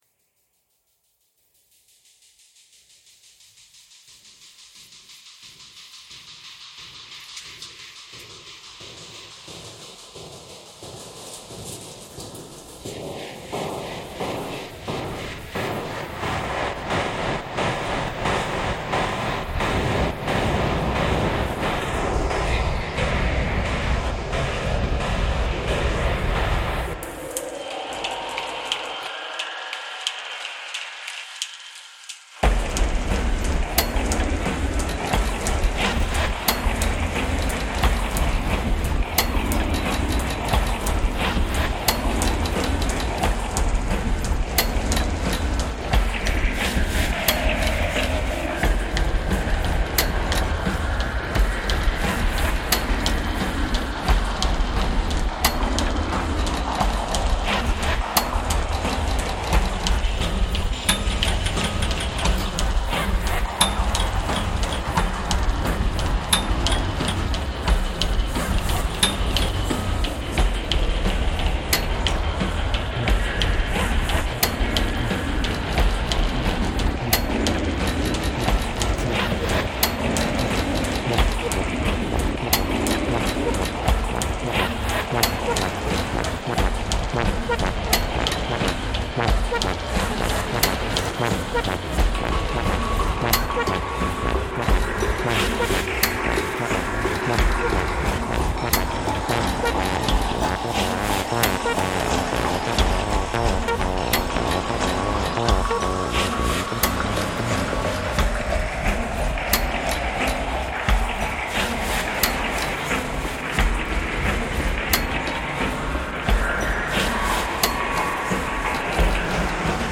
Helsinki tram ride reimagined